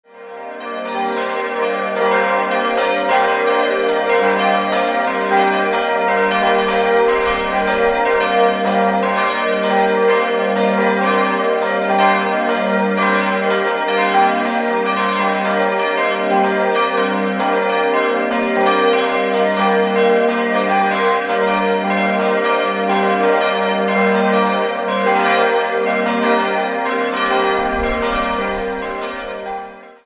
Nach einem Turmeinsturz 1696 musste das Gotteshaus wieder aufgebaut werden. 4-stimmiges ausgefülltes G-Moll-Geläute: g'-b'-c''-d'' Die 4 Glocken wurden 1964 von Friedrich Wilhelm Schilling gegossen und wiegen 904, 520, 374 und 258 kg.